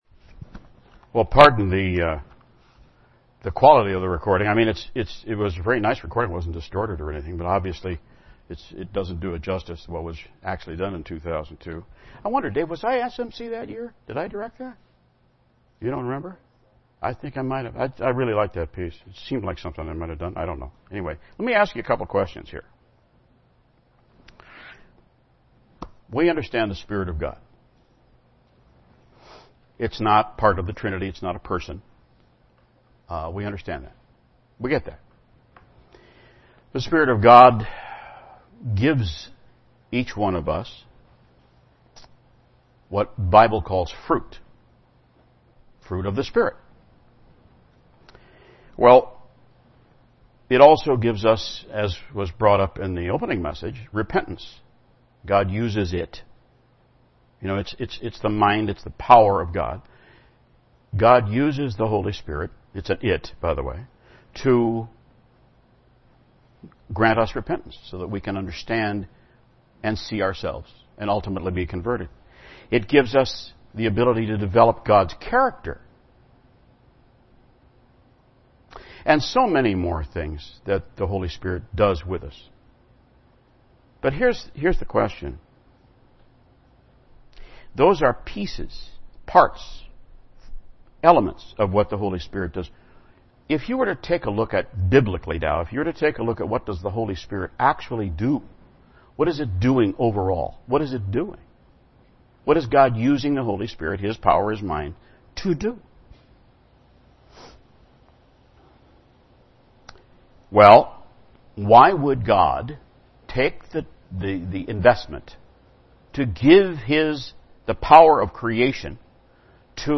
Given in Beloit, WI
UCG Sermon God's plan Holy Spirit Studying the bible?